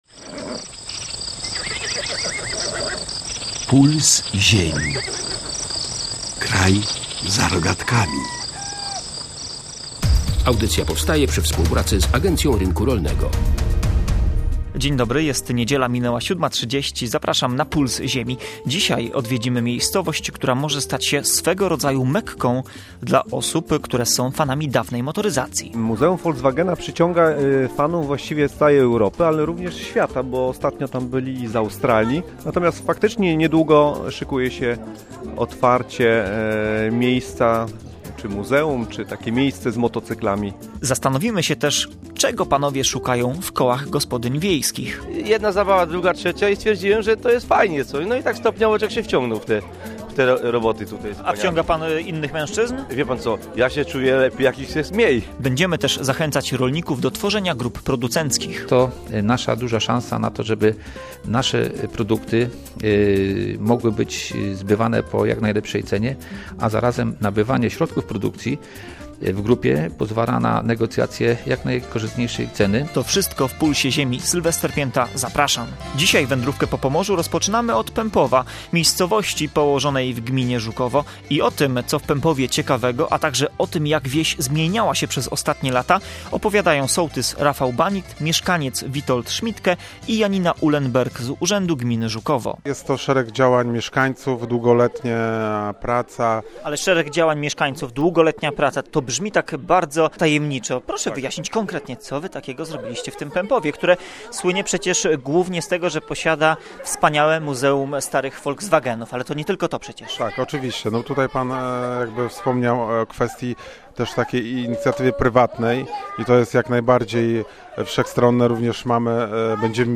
Zaprezentowaliśmy też fragmenty książki w interpretacji Aleksandra Machalicy.